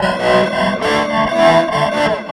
47 GUIT 2 -L.wav